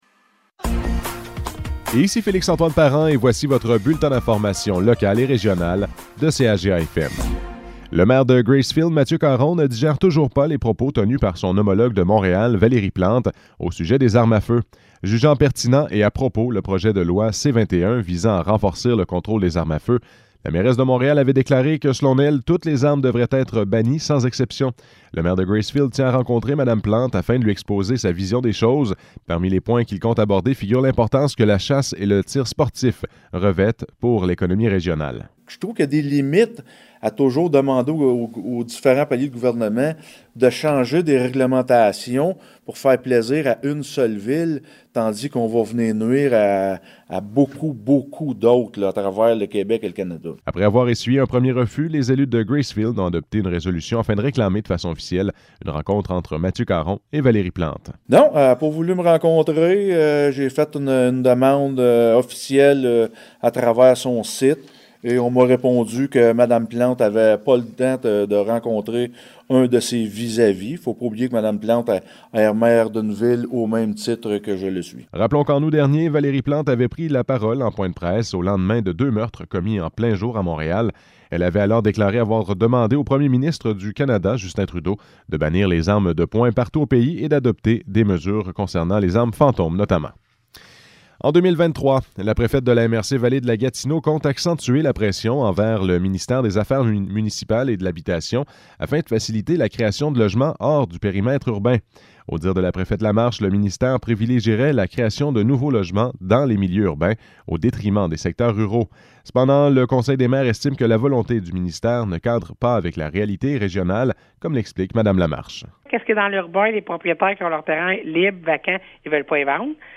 Bulletins de nouvelles